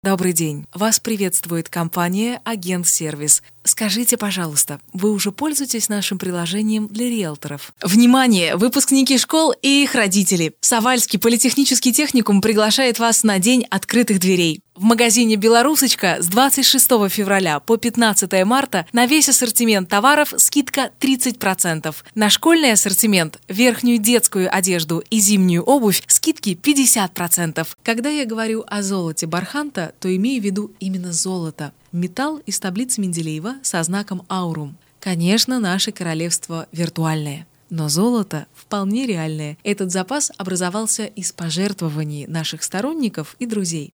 Пример звучания голоса
ЧИСТОЕ ДЕМО
Жен, Другая
Микрофон SHURE SM7B